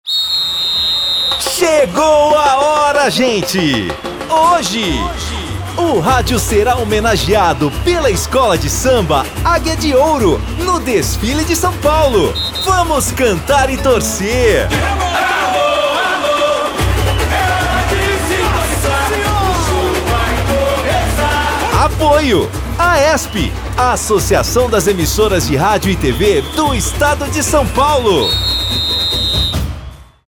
Sob a coordenação da AESP (Associação das Emissoras de Rádio e TV do Estado de São Paulo), foram distribuídos spots de rádio com uma contagem regressiva para o desfile da Águia de Ouro, que ocorrerá neste sábado.